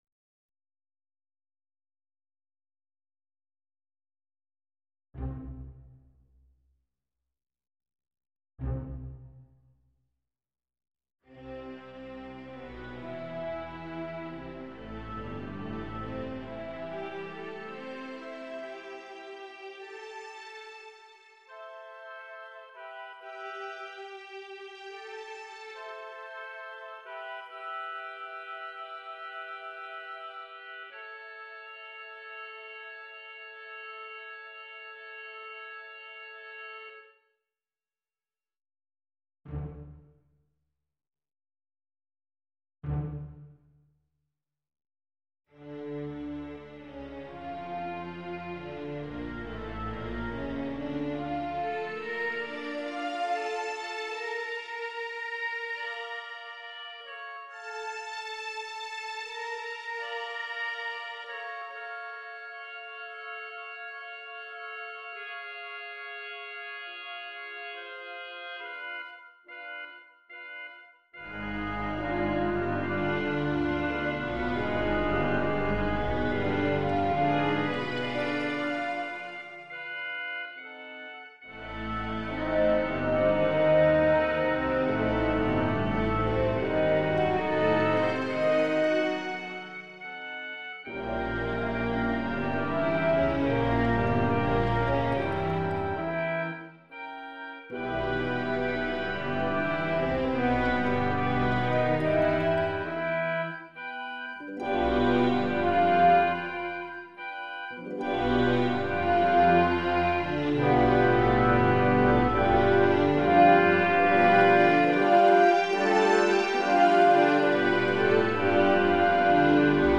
This is Les Prelude by Franz Liszt. Pieces of this music were the background theme to Flash Gordon/Buster Crabbe movies.